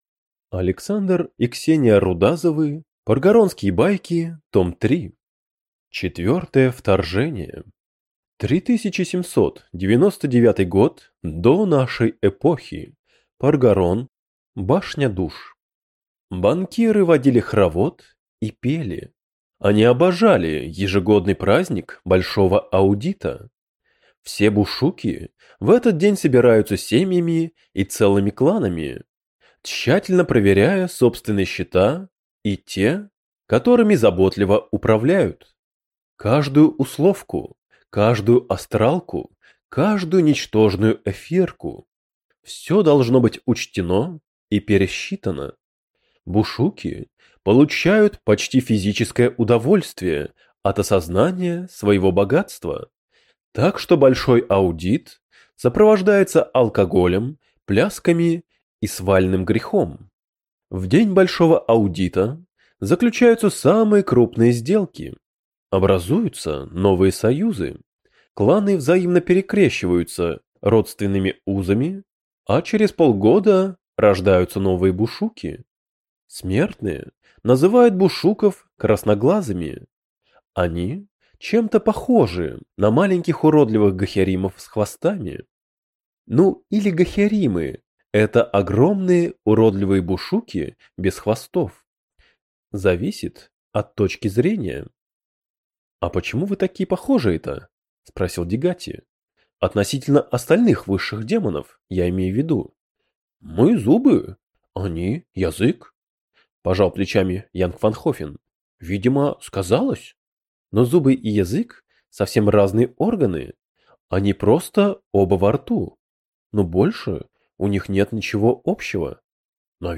Аудиокнига Паргоронские байки. Том 3 | Библиотека аудиокниг